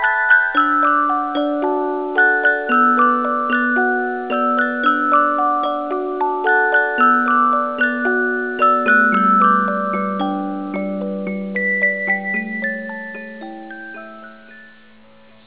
It was a CD of popular music performed on music boxes.